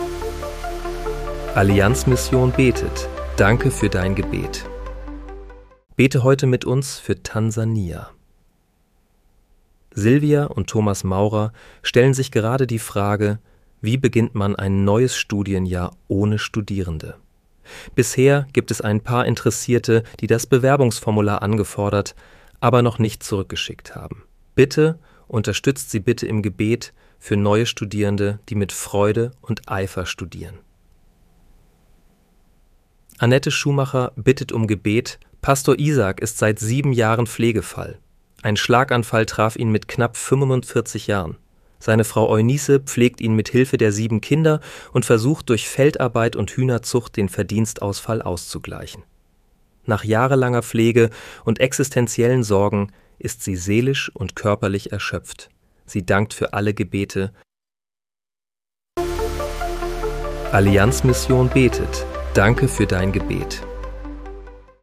Bete am 07. Januar 2026 mit uns für Tansania. (KI-generiert mit der